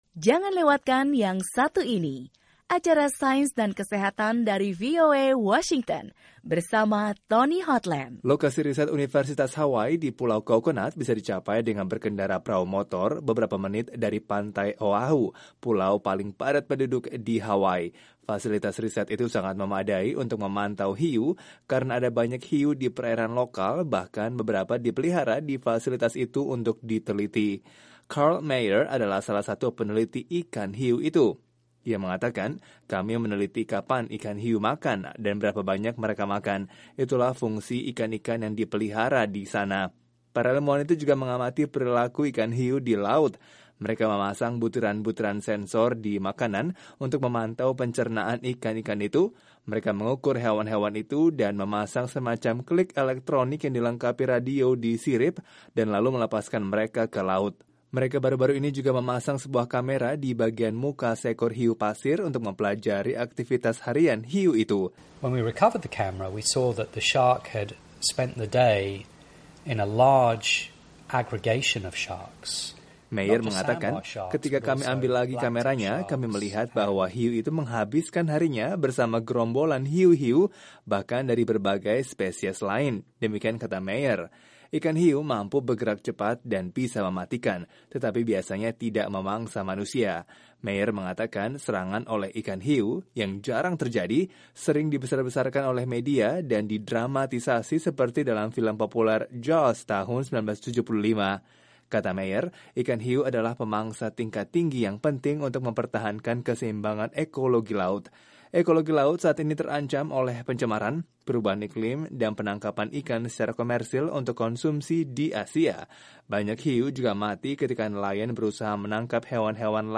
Laporan